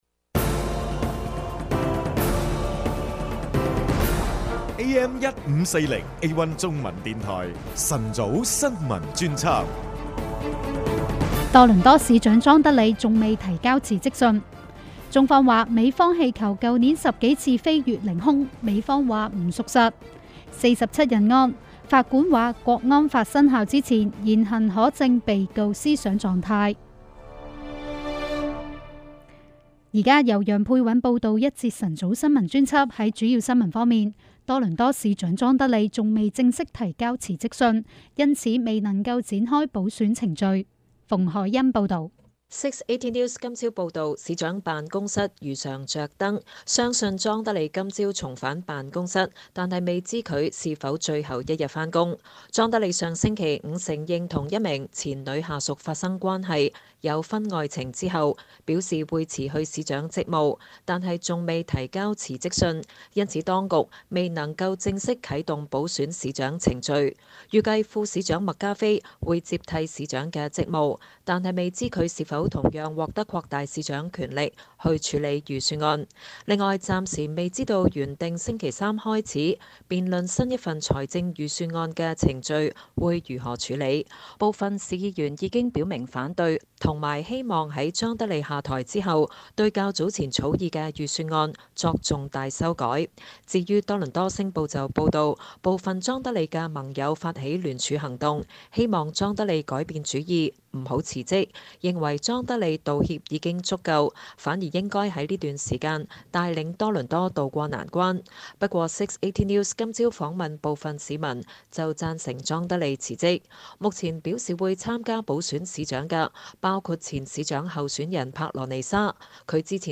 【有聲新聞】星島A1中文電台 晨早新聞專輯